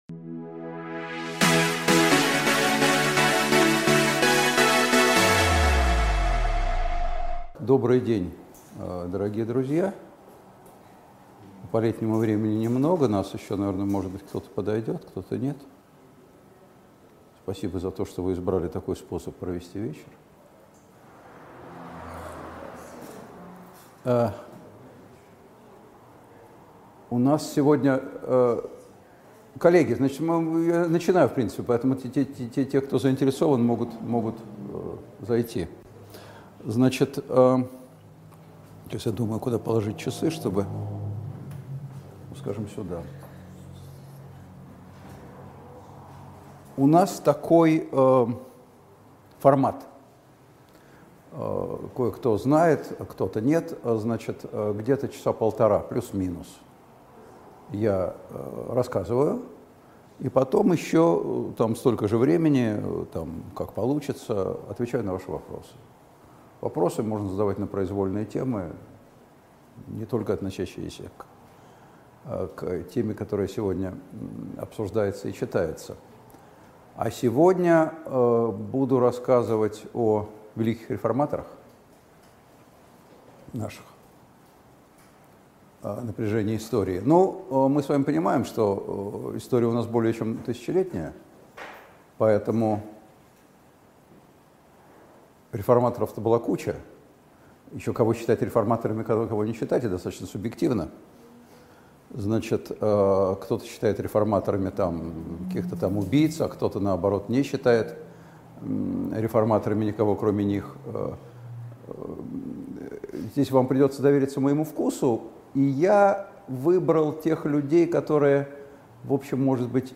Аудиокнига Прогрессивные реформаторы отечественной истории: через тернии к звездам | Библиотека аудиокниг
Aудиокнига Прогрессивные реформаторы отечественной истории: через тернии к звездам Автор Николай Сванидзе Читает аудиокнигу Николай Сванидзе.